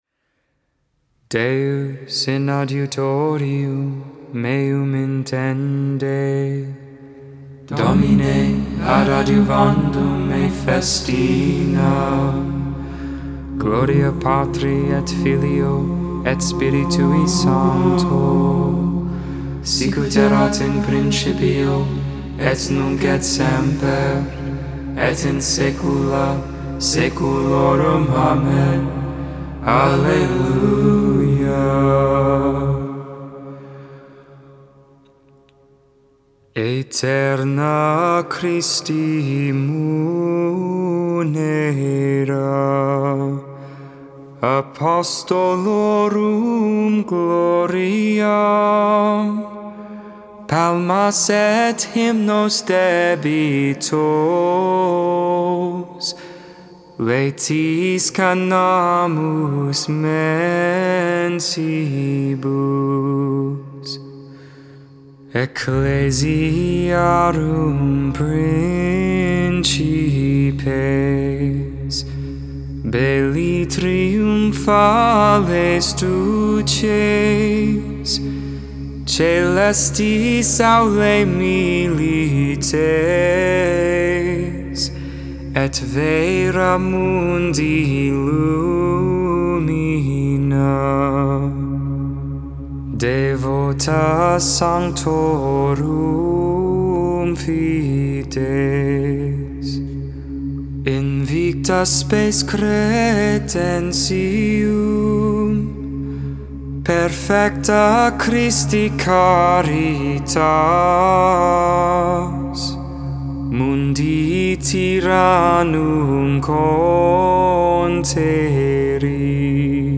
Vespers, Evening Prayer on the 17th Monday in Ordinary Time, July 25th, 2022.